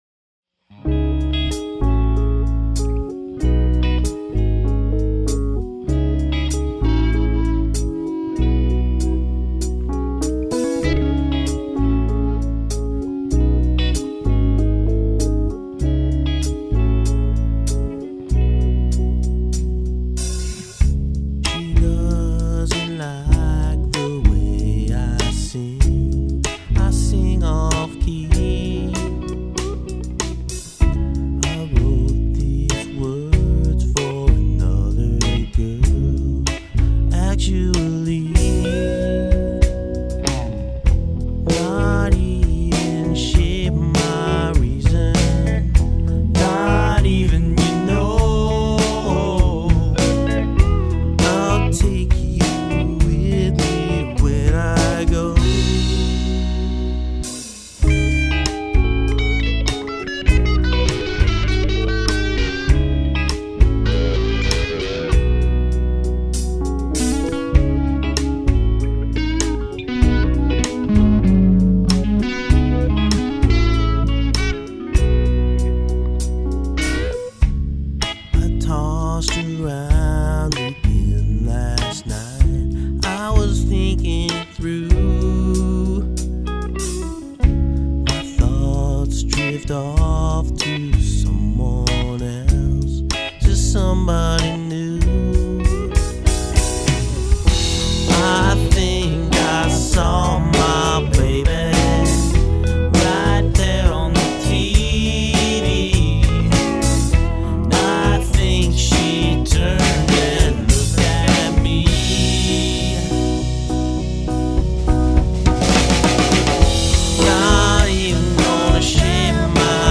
in which delicate songwriting is interwoved by complex jams.
studio recordings